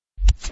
button.wav